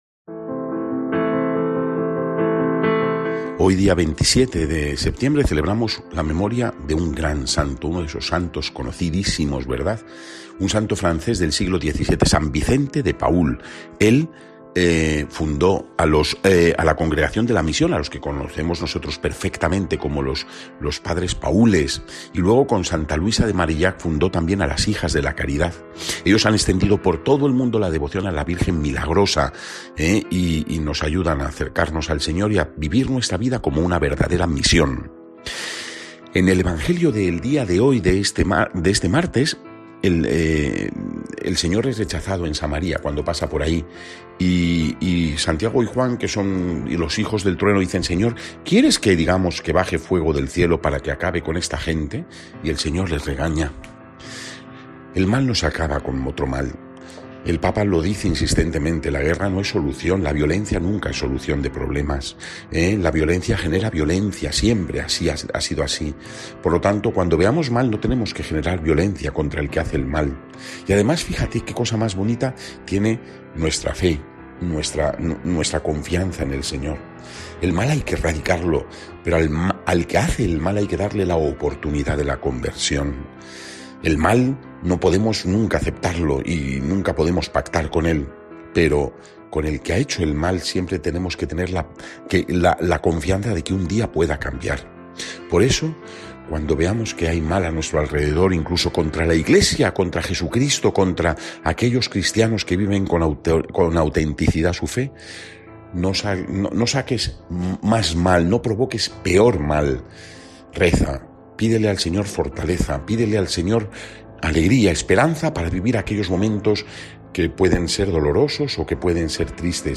Evangelio según san Lucas (9,51-56)
Meditación